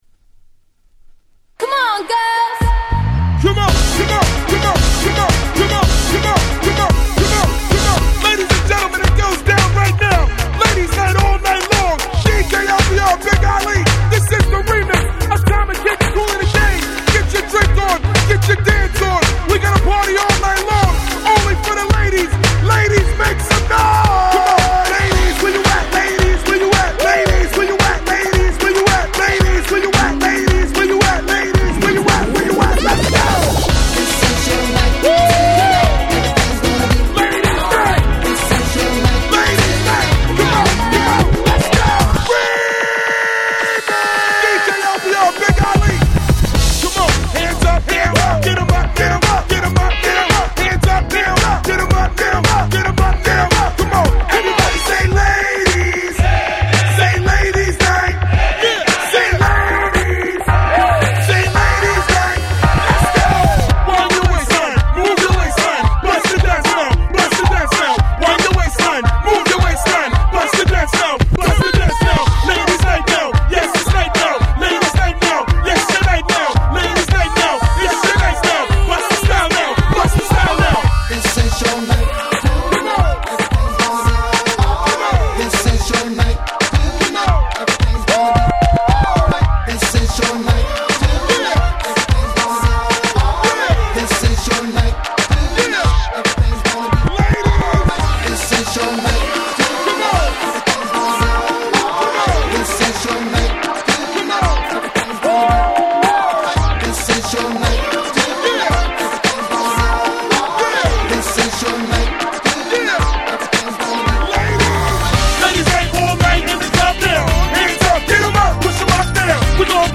後半の転調する部分とアゲアゲ隊長のコラボレーション…何と言う化学反応…！！